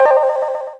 Buzzer.wav